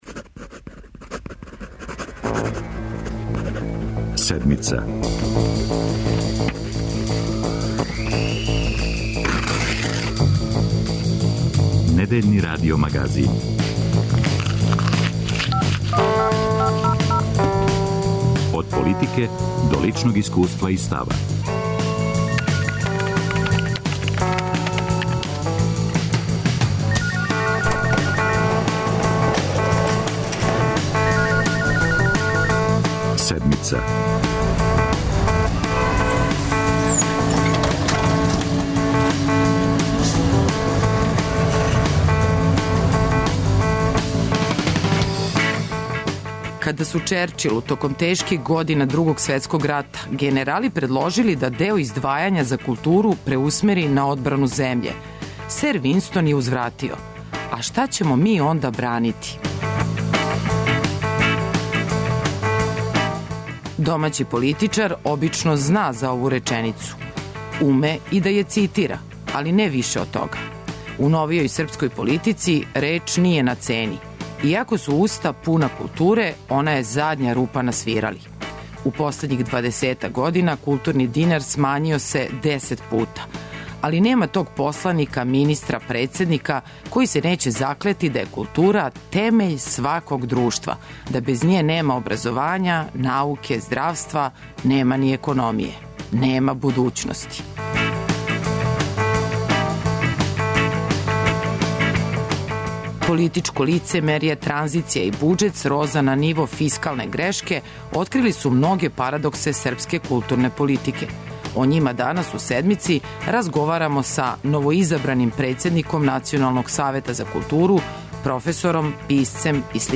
Ове недеље за Седмицу говори сликар и писац Милета Продановић.